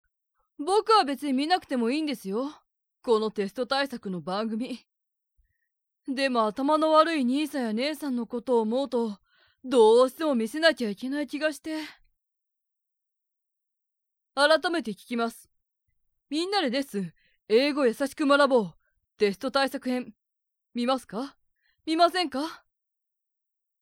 収録は、４４１００Hz、１６ビット、モノラルで行いました。
（マイク：SHURE/SM-58、インターフェイス：ローランド/UA-11）
中〜低音の少年声。真面目君。